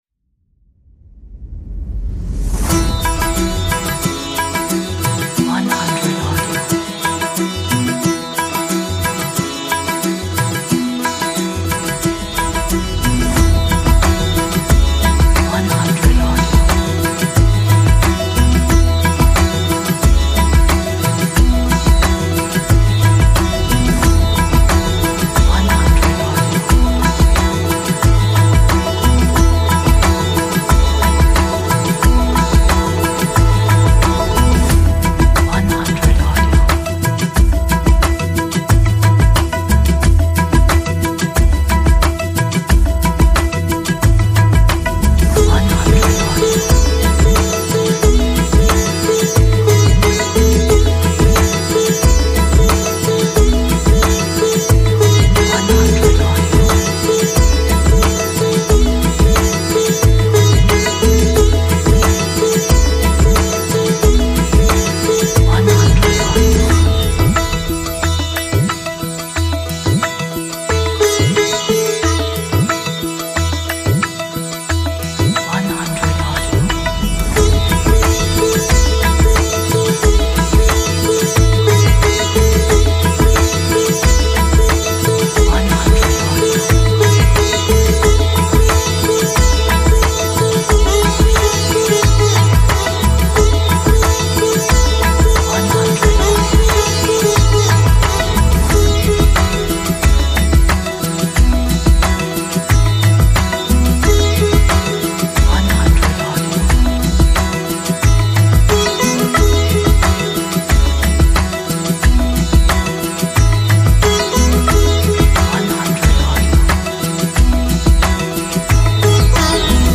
Motivational track of Indian music.